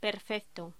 Locución: Perfecto
voz
Sonidos: Hostelería